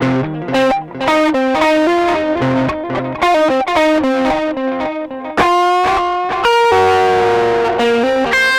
Track 15 - Guitar 01.wav